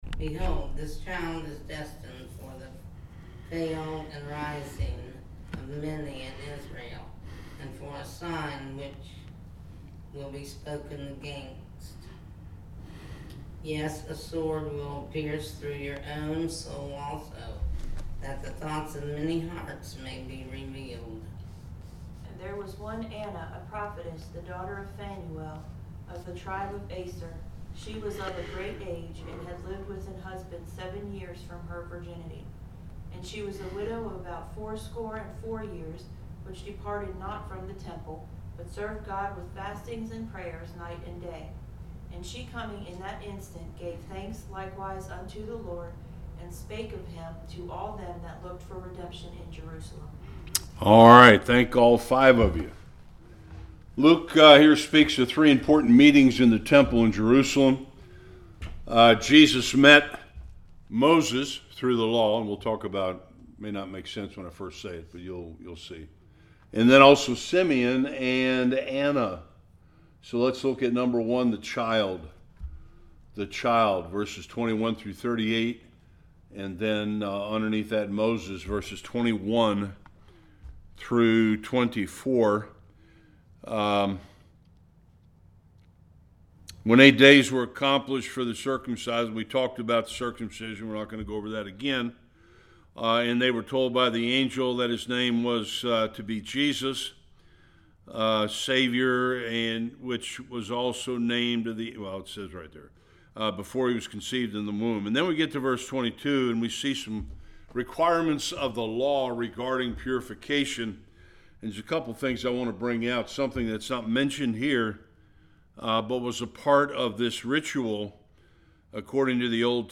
22-38 Service Type: Bible Study The worship and praise songs of Simeon and Anna.